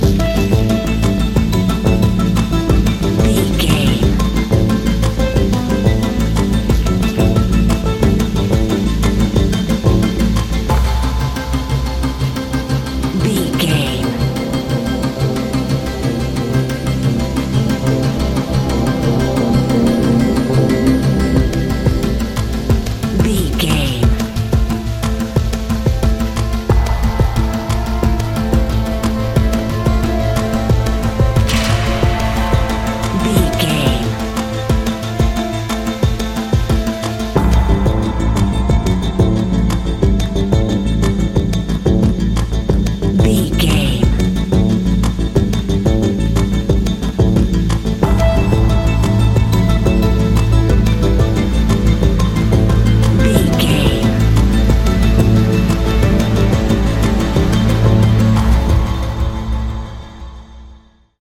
Aeolian/Minor
synthesiser
drum machine
tension
ominous
dark
haunting
spooky